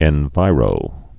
(ĕn-vīrō)